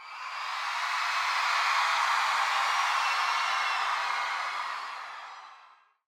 sfx_crowd_hype.ogg